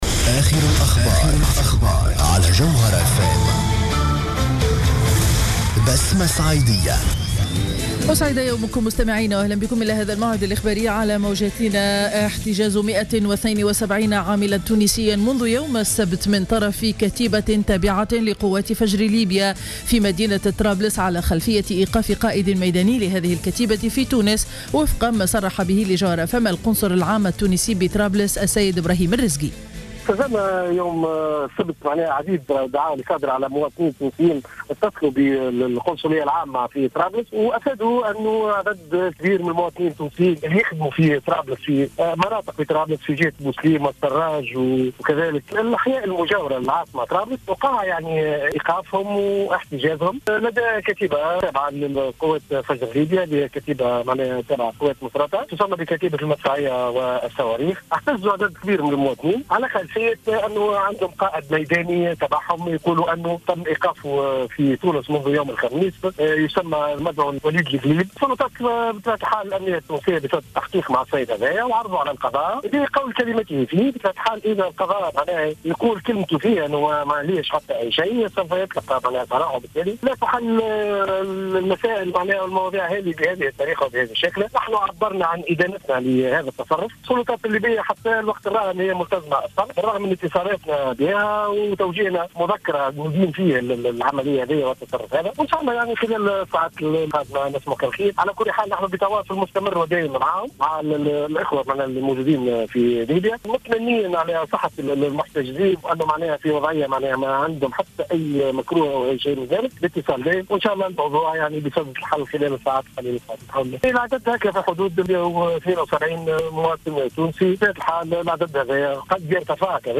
نشرة أخبار السابعة صباحا ليوم الإثنين 18 ماي 2015